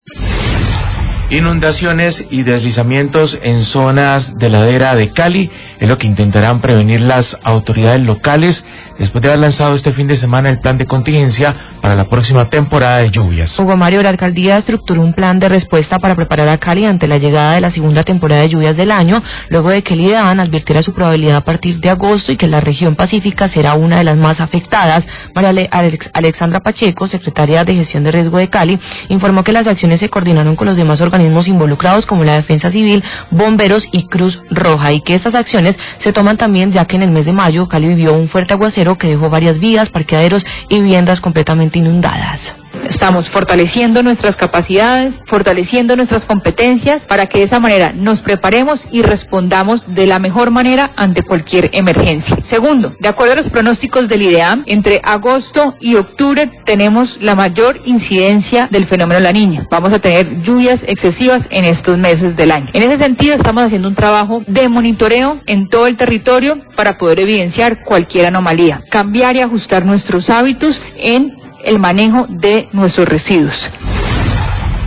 Sria Gestión Riesgo Cali habla de plan contingencia para temporada de lluvias
Radio